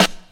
• Old School Good Hip-Hop Snare Sound F# Key 232.wav
Royality free snare drum tuned to the F# note. Loudest frequency: 2282Hz
old-school-good-hip-hop-snare-sound-f-sharp-key-232-Fuj.wav